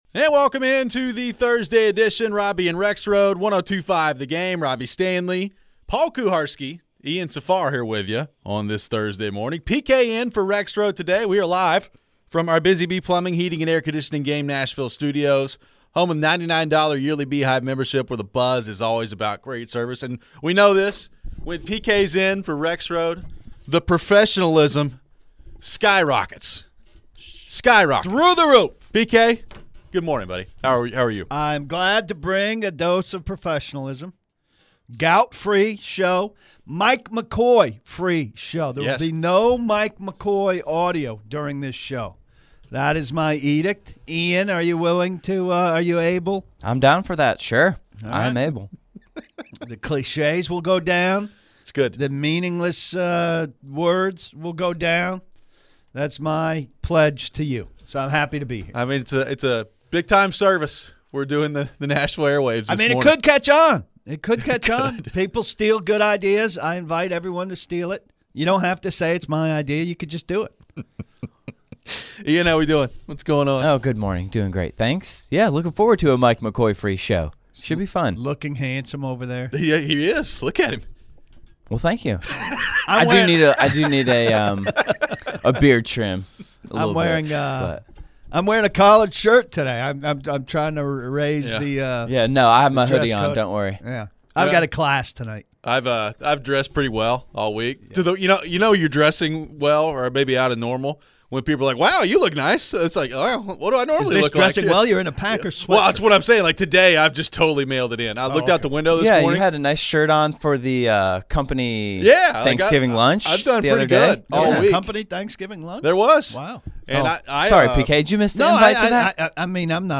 We head to the phones. How much could ownership hurt the coaching search?...